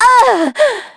Lucikiel_L-Vox_Damage_kr_03.wav